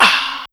WDB Breath.wav